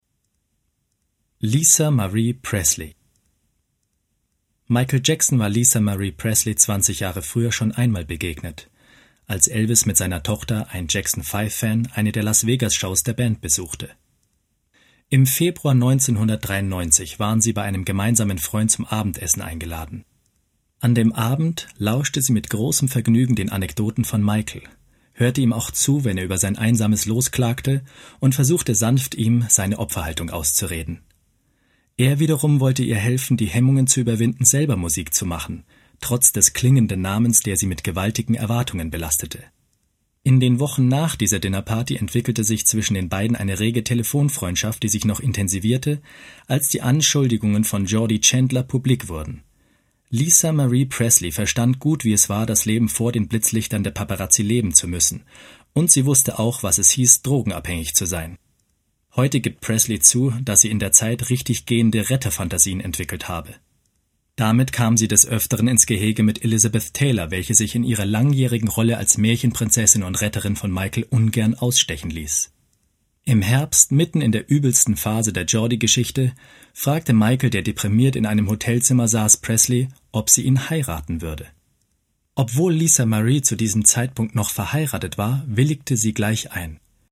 Auf der Basis von Hanspeter Künzler’s Biographie “Black or White” schildert das Hörbuch die Entwicklung und die sagenhaften Erfolge des grandiosen Weltstars wie auch die schier endlose Kette von Skandalen, die Michael Jackson in den 40 Jahren seiner atemberaubenden Karriere begleiten.